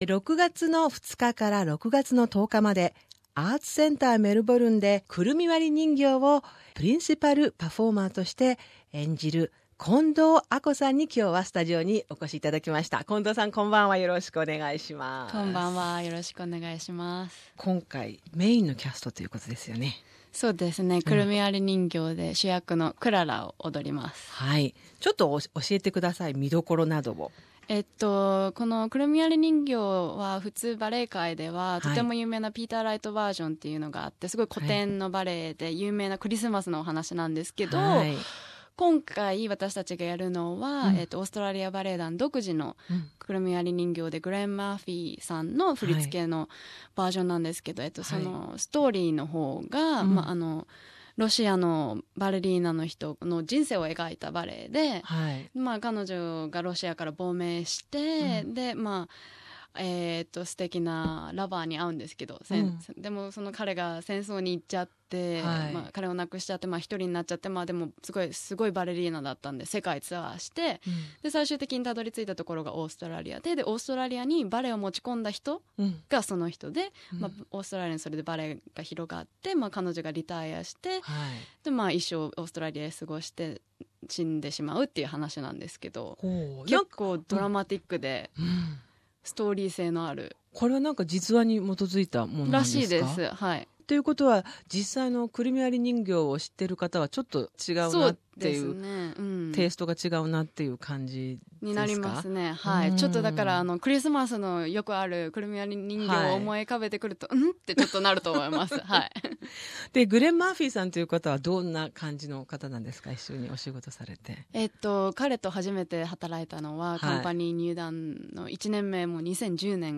６月２日～１０日までメルボルン・アーツセンターで公演するオーストラリア・バレエ団の「くるみ割り人形」の主役、プリンシパル・バレリーナの近藤亜香さんに、メルボルン公演前に、話を聞いた。